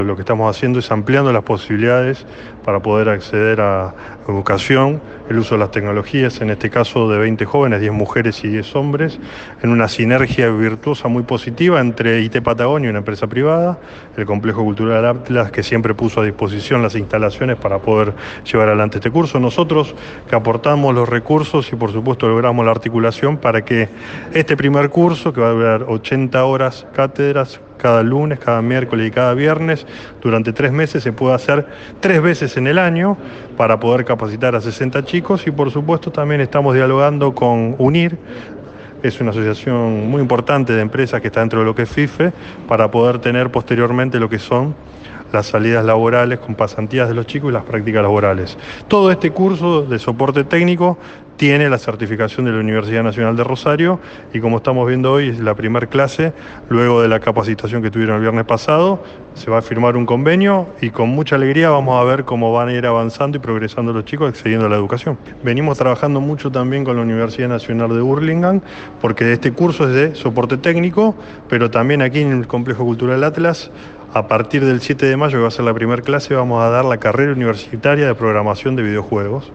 Con el objetivo de promover los cursos de oficio y ampliar las oportunidades, iniciaron este lunes las capacitaciones Soporte Técnico JENI en el Complejo Cultural Atlas. En este marco, el legislador Marcos Cleri, quien lleva adelante el proyecto, conversó con el móvil de Radio Boing.